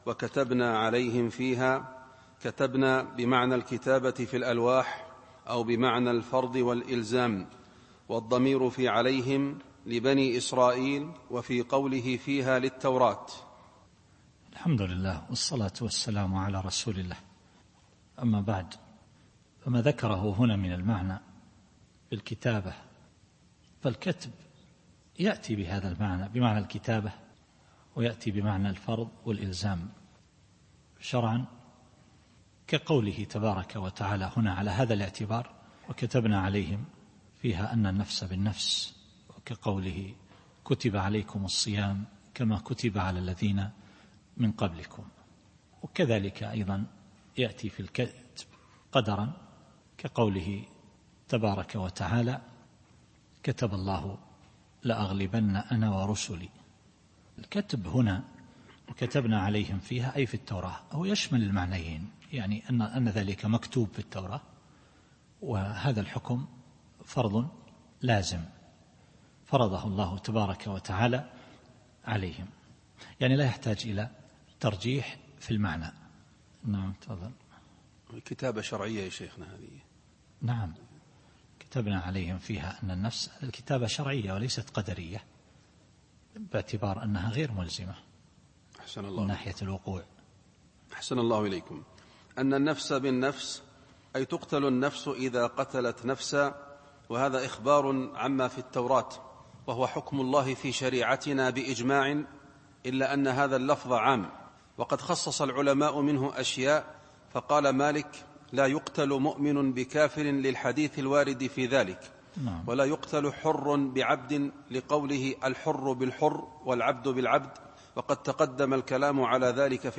التفسير الصوتي [المائدة / 45]